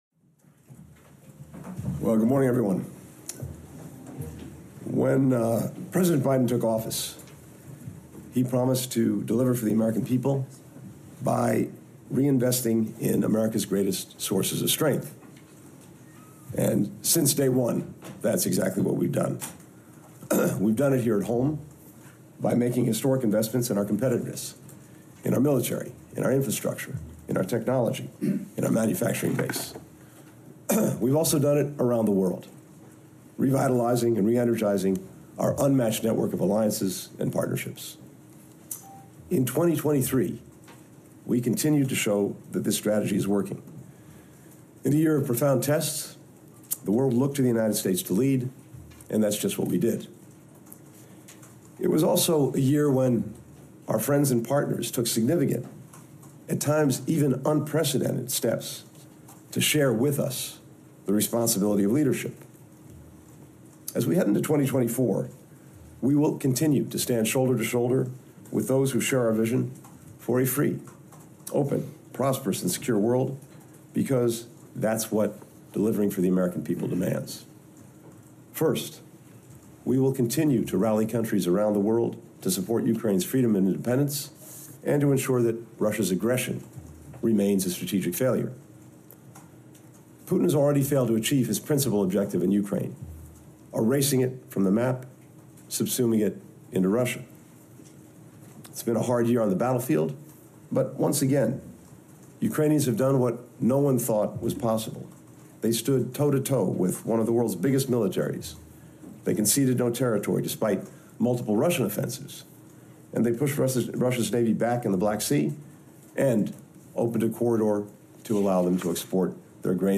Antony J. Blinken: End of Year Presser 2023 (transcript-audio-video)